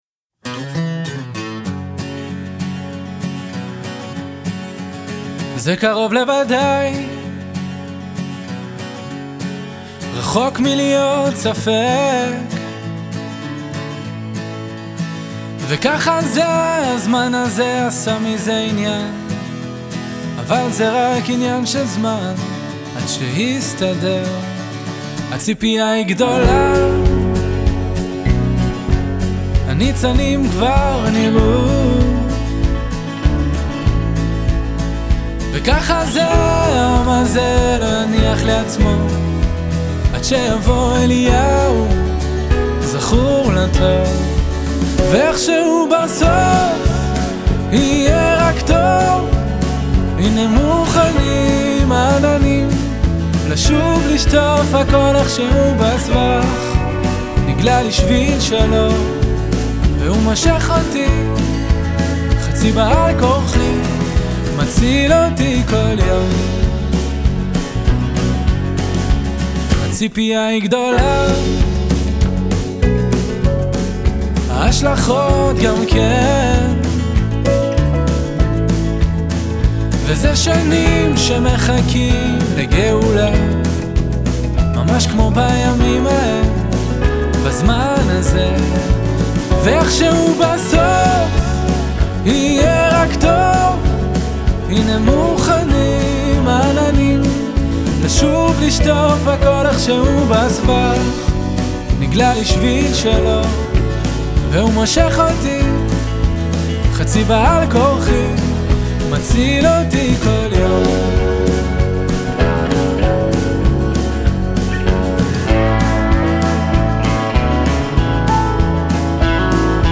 הקלידן המקצועי